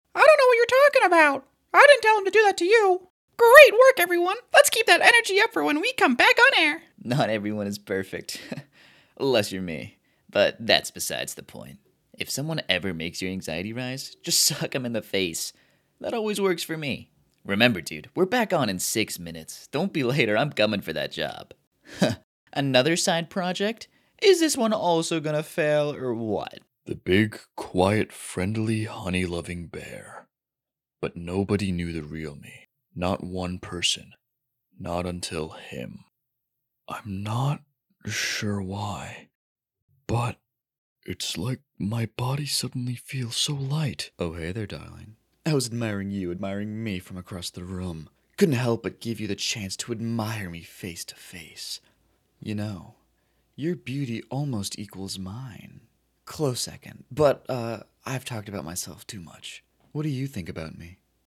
Character Demos